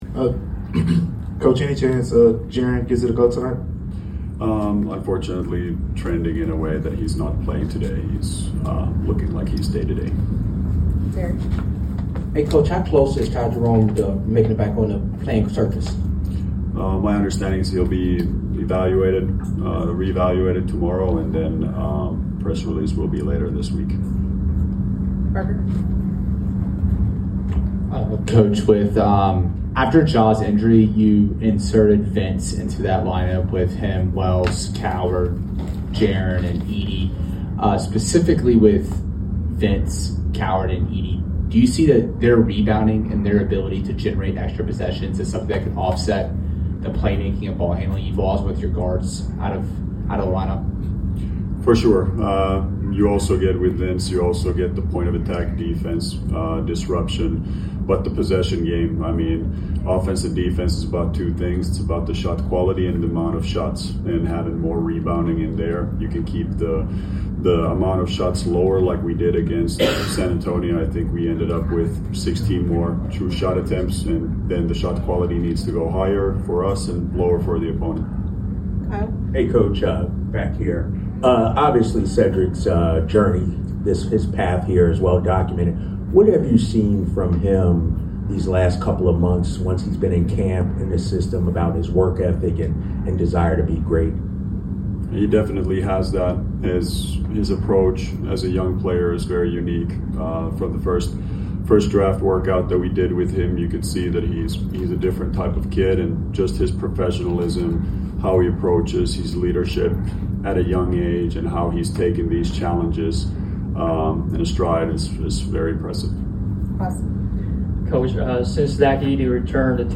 Memphis Grizzlies Coach Tuomas Iisalo Pregame Interview before taking on the Sacramento Kings at FedExForum.